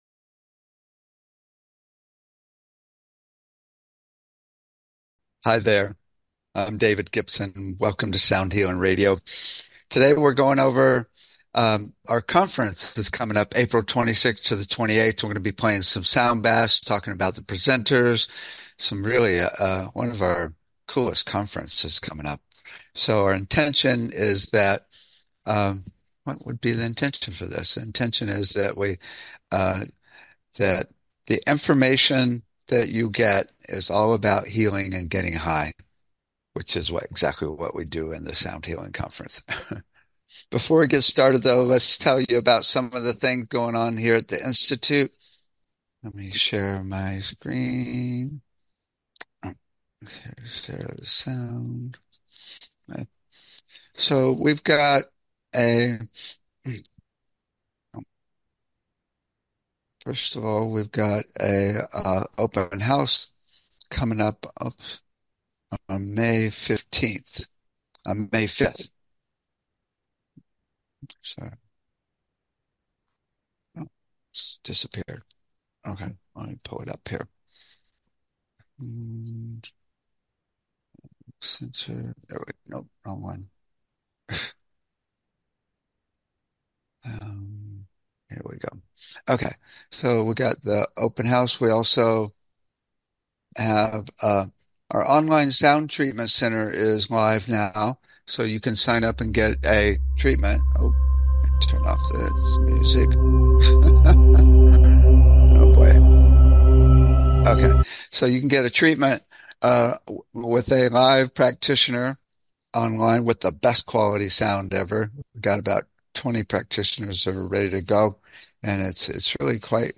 Talk Show Episode, Audio Podcast, Sound Healing and Upcoming Conference on April 26th - 28th, Sound Baths and Presenters on , show guests , about Upcoming Conference on April 26th,Sound Baths and Presenters, categorized as Education,Health & Lifestyle,Sound Healing,Emotional Health and Freedom,Mental Health,Science,Self Help,Spiritual,Technology